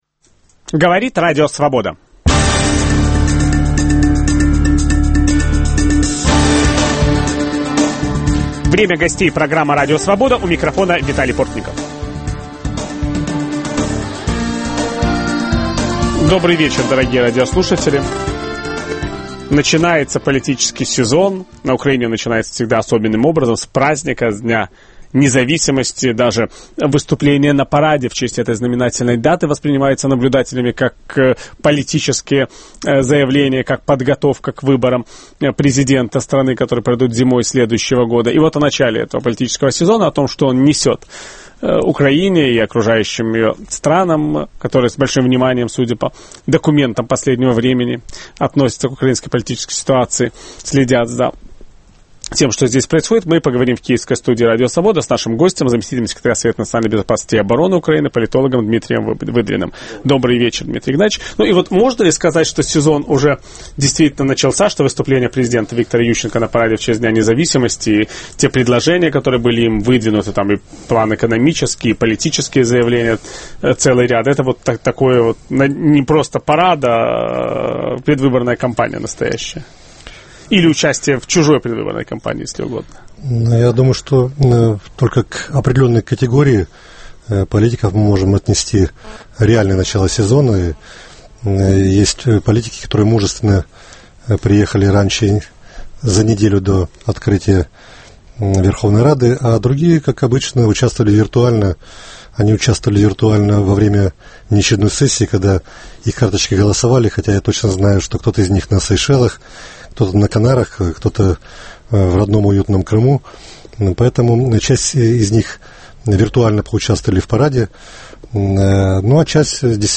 Поговорим о начале политического сезона на Украине, что он несет Украине и окружающим ее странам. В киевской студии Радио Свобода - заместитель секретаря Совета национальной безопасности и обороны Украины, политолог Дмитрий Выдрин.